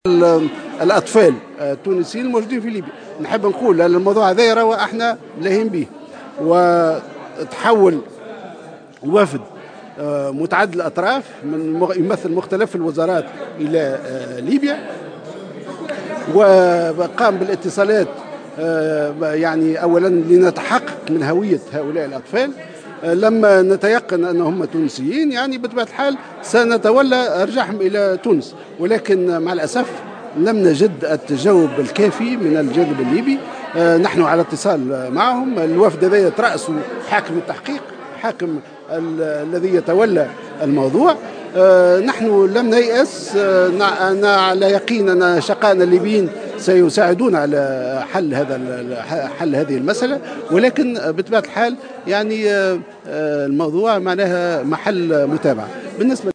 وأضاف في تصريح لمراسلة "الجوهرة أف أم" اثر جلسة برلمانية عامة، أن وفدا متعدّد الأطراف يمثّل مختلف الوزارات برئاسة حاكم التحقيق تحول إلى ليبيا وقام باتصالات مع الجانب الليبي للتثبت من هويات الأطفال إلا انه لم يتم تحقيق التجاوب الكافي من الجانب الليبي.